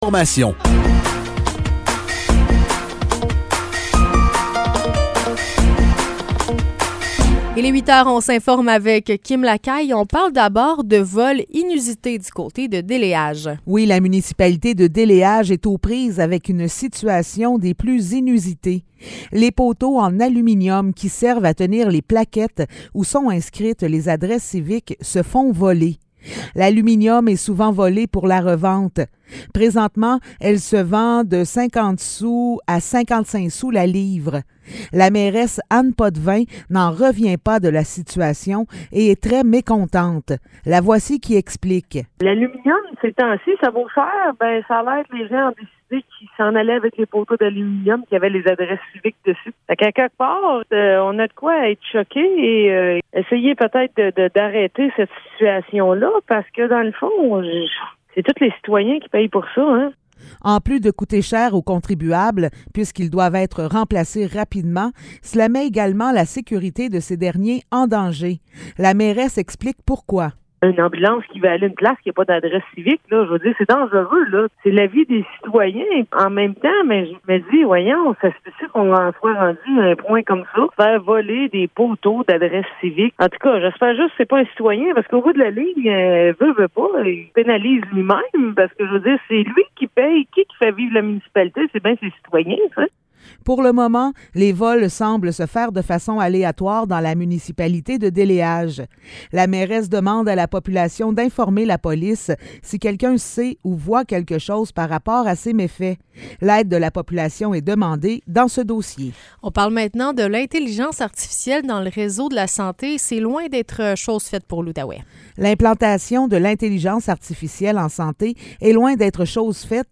Nouvelles locales - 10 août 2023 - 8 h